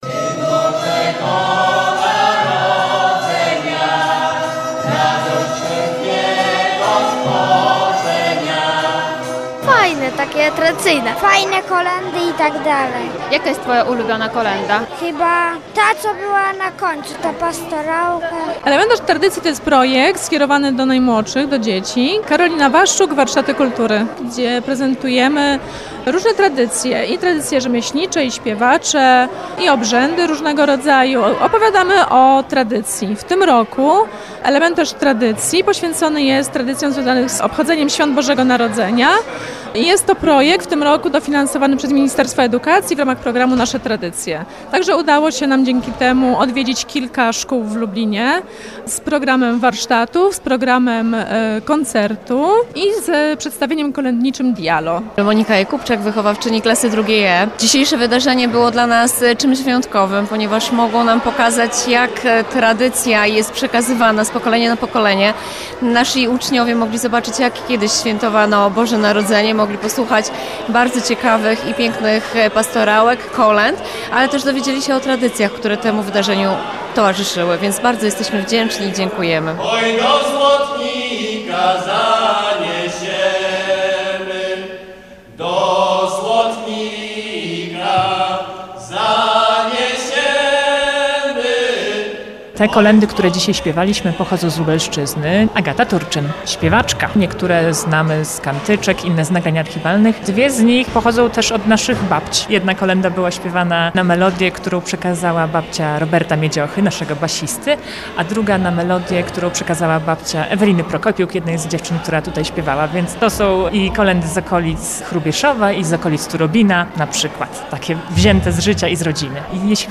W ramach projektu „Elementarz tradycji” odbył się koncert tradycyjnych kolęd przygotowany przez osoby kontynuujące praktyki przekazane przez wiejskich śpiewaków, śpiewaczki i instrumentalistów z Lubelszczyzny.
W programie znalazły się kolędy życzące, apokryficzne, pastorałki oraz fragmenty widowiska herodowego. Całość przygotowały i poprowadziły grupy śpiewacze Zielona Girlanda, Dziki Bez i Kapela Bornego.